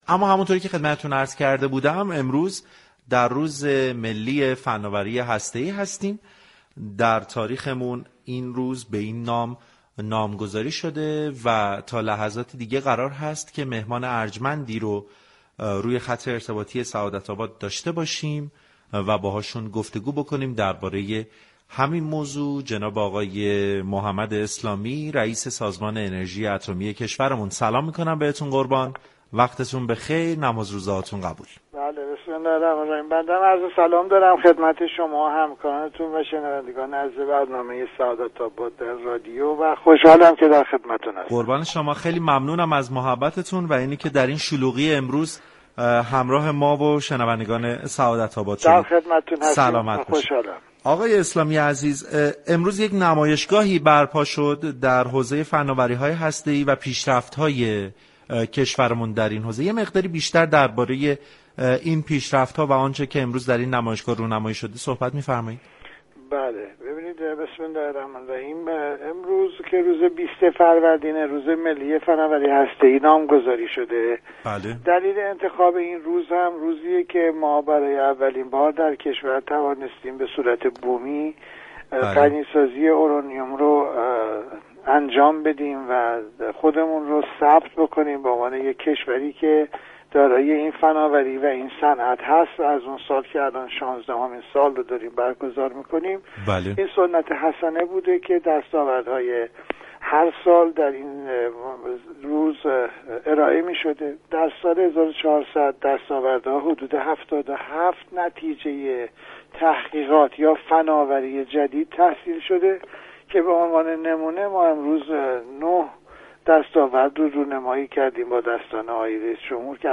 به گزارش پایگاه اطلاع رسانی رادیو تهران، محمد اسلامی رئیس سازمان انرژی اتمی كشور در روز ملی فناوری هسته ای با برنامه سعادت آباد رادیو تهران گفتگو كرد.